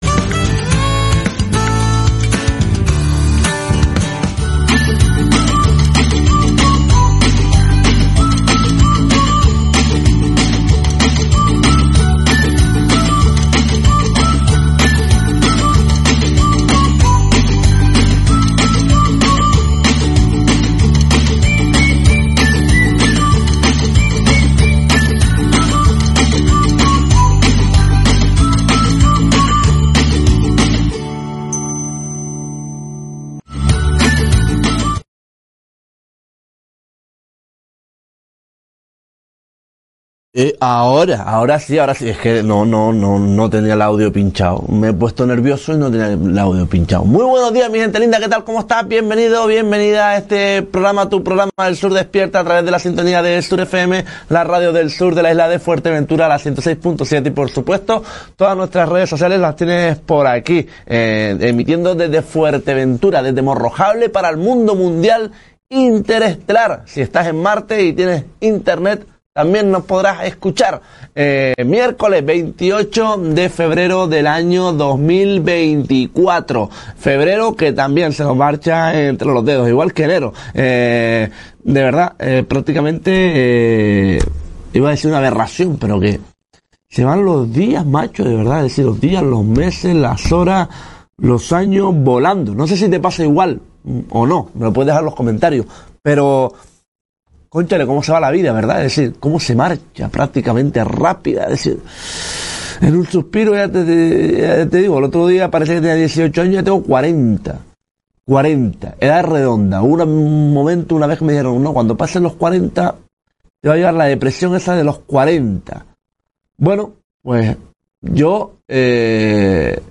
Comenzamos el programa matinal de SurFM, ESD hablando de las pantallas.